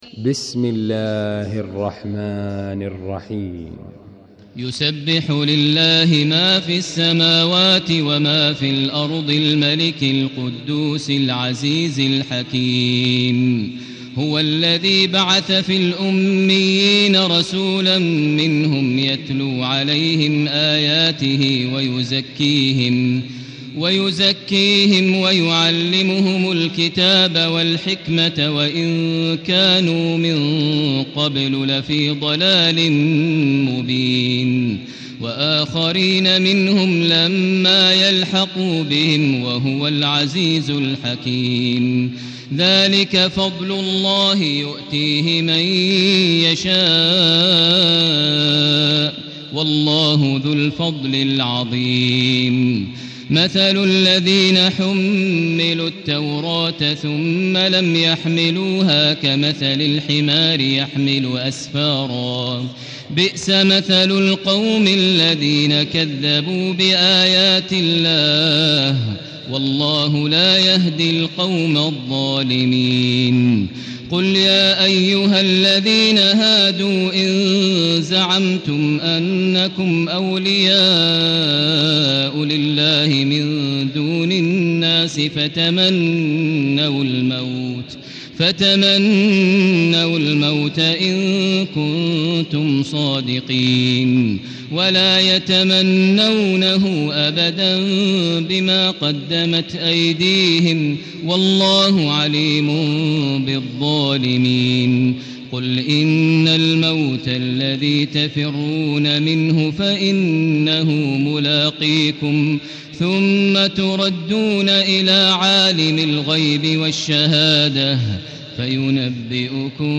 المكان: المسجد الحرام الشيخ: فضيلة الشيخ ماهر المعيقلي فضيلة الشيخ ماهر المعيقلي الجمعة